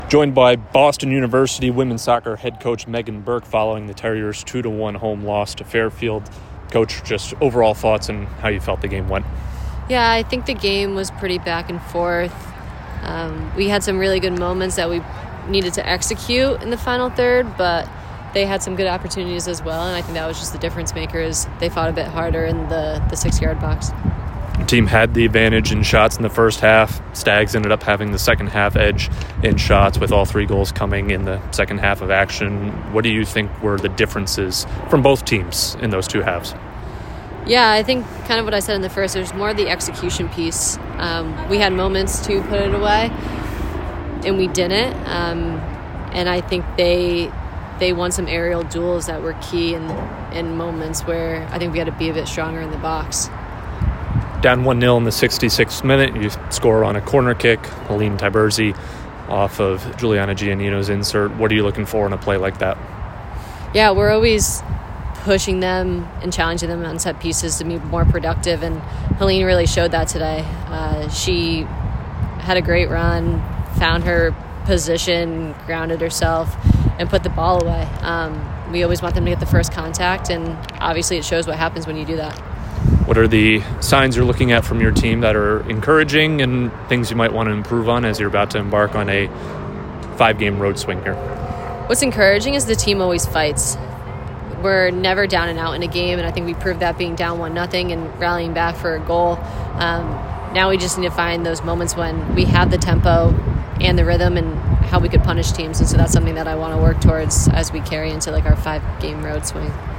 Women's Soccer / Fairfield Postgame Interview (8-21-25)